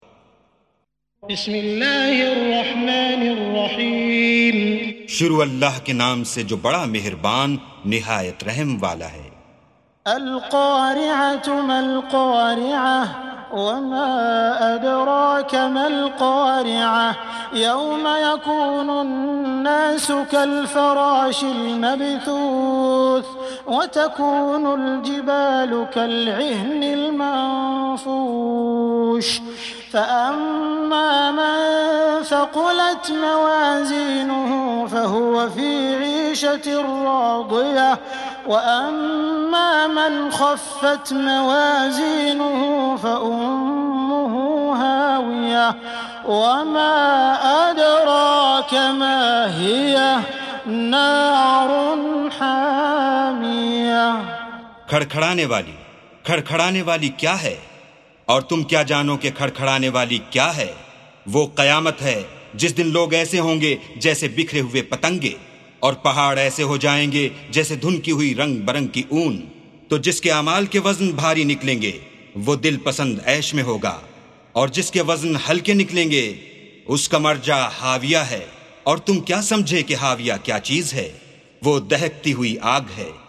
سُورَةُ القَارِعَةِ بصوت الشيخ السديس والشريم مترجم إلى الاردو